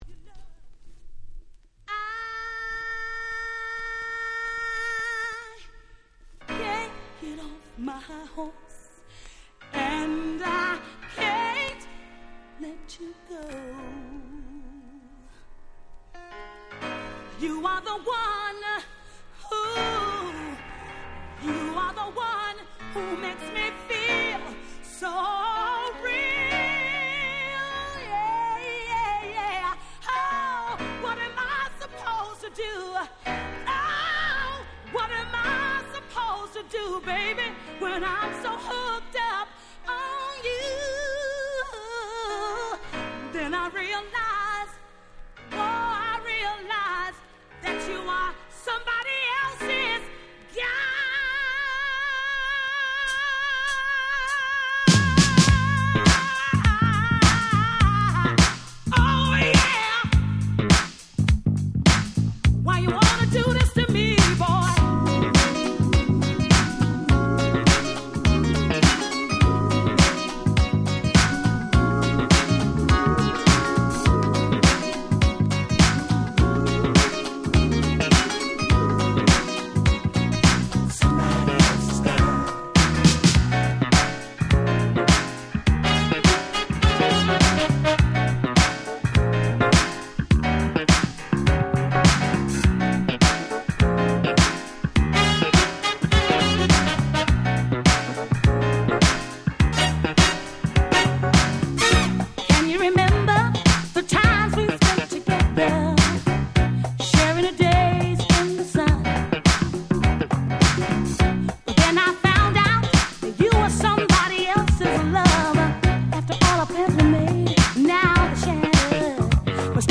87' Nice Dance Classics / Disco コンピレーション！！